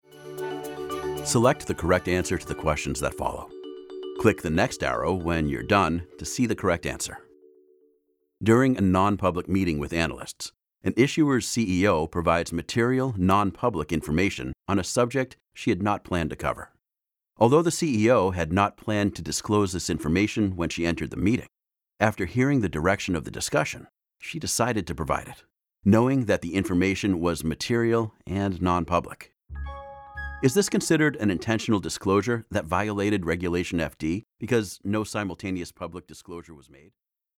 Mature Adult, Adult
The friendly, accessible, trustworthy, authoritative, “coffee-laced-with-caramel” voice of the nerdy, fun dad next door.
standard us
phone message